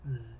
snd_graze.wav